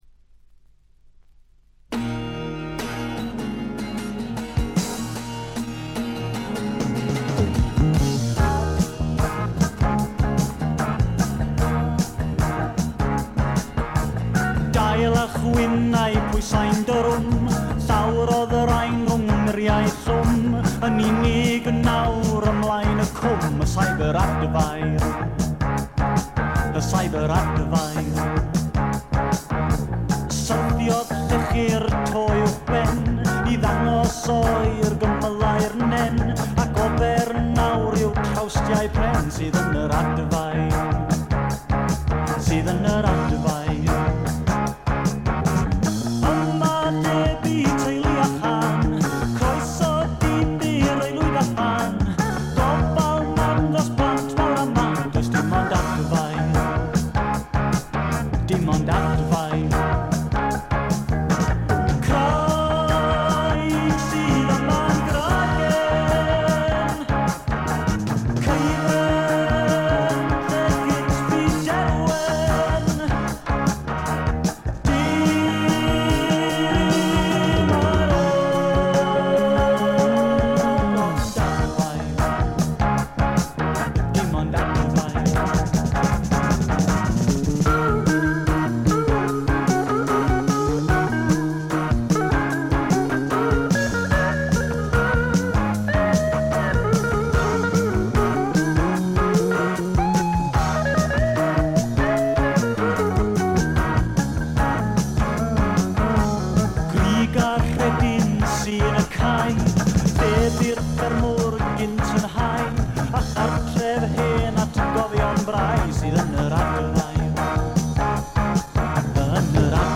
内容はフォーク、アシッド、ポップ、ロックと様々な顔を見せる七変化タイプ。
試聴曲は現品からの取り込み音源です。